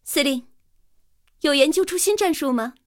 KV-1查看战绩语音.OGG